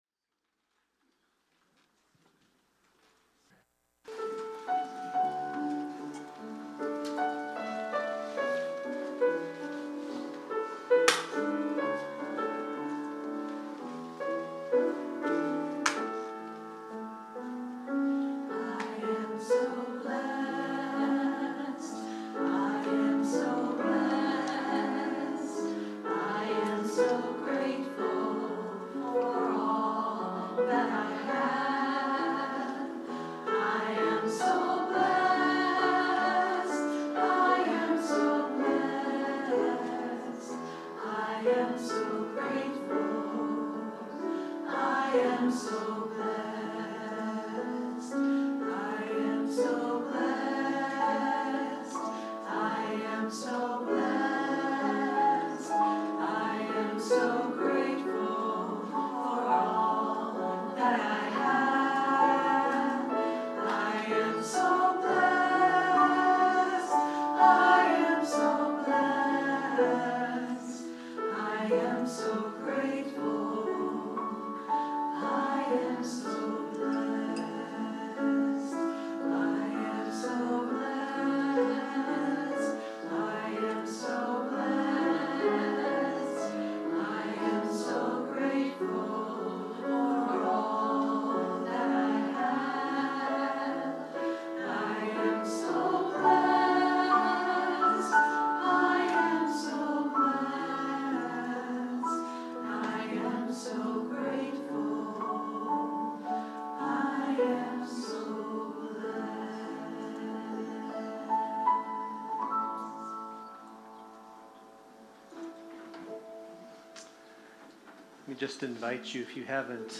The audio recording (below the video clip) is an abbreviation of the service. It includes the Meditation, Message, and Featured Song.